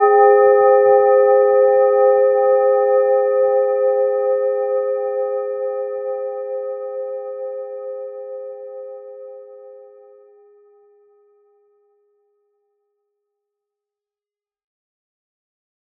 Gentle-Metallic-2-C5-mf.wav